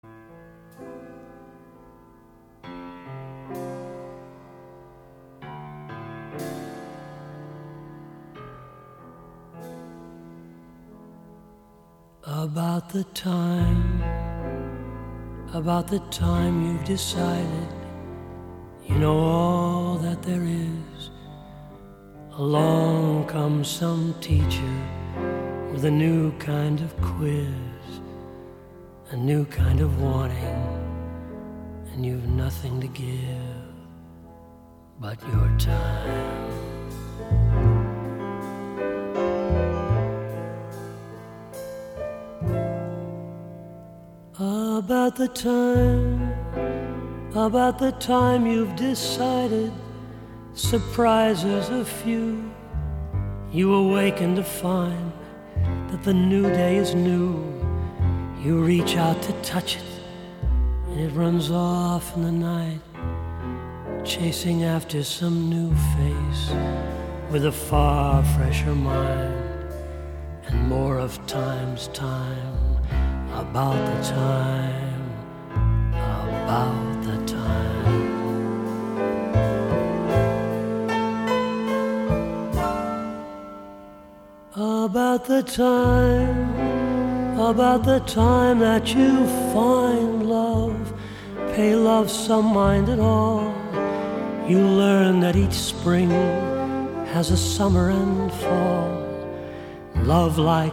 ★柔和而富磁性的歌聲，輕緩悠揚的爵士風情，為您在夜間點上一盞綻放溫暖光芒的燈。
低沉富磁性的嗓音更增添了歲月的風采，傳統爵士的鋼琴、吉他、薩克斯風外，更以大提琴、笛子等增添浪漫悠揚的感性